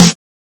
• 00s Prominent Steel Snare Drum Sound F Key 52.wav
Royality free snare drum sound tuned to the F note. Loudest frequency: 2327Hz
00s-prominent-steel-snare-drum-sound-f-key-52-NOd.wav